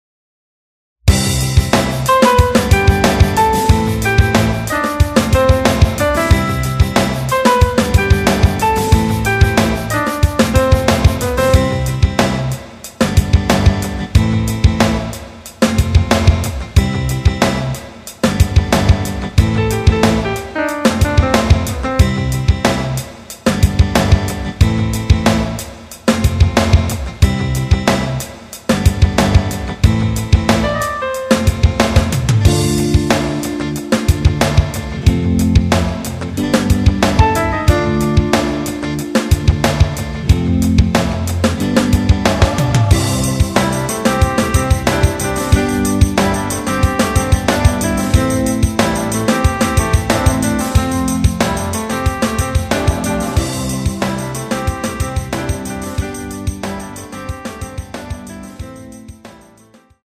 부담없이즐기는 심플한 MR
앞부분30초, 뒷부분30초씩 편집해서 올려 드리고 있습니다.
중간에 음이 끈어지고 다시 나오는 이유는